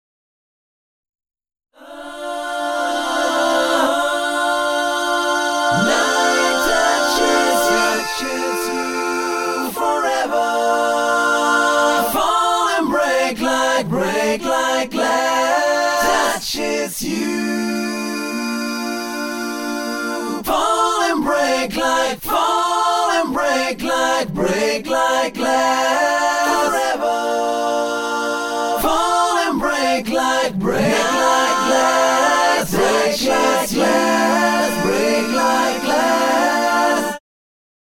Samples of early demos, unused takes and such.